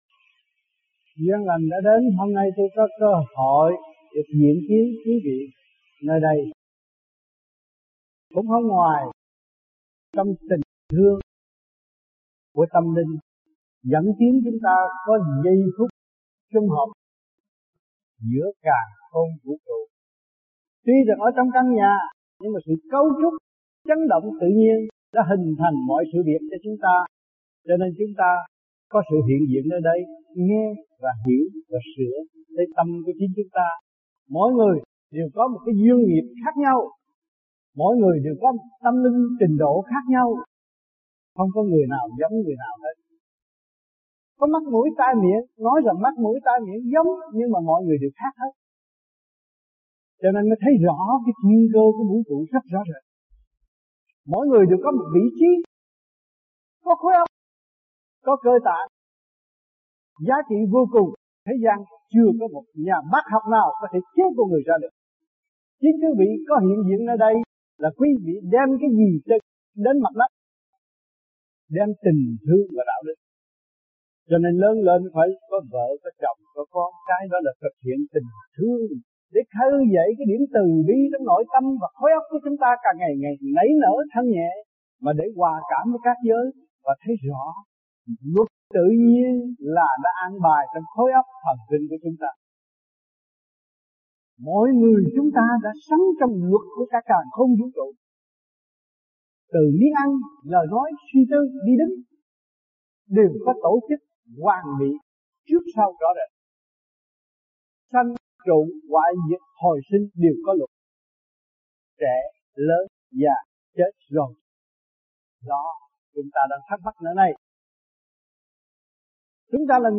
1990-06-08 - SYDNEY - TÌNH THƯƠNG VÀ ĐẠO ĐỨC - THẦY THUYẾT GIẢNG CHO CỘNG ĐỒNG VIỆT NAM TẠI SYDNEY